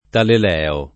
Taleleo [ talel $ o ]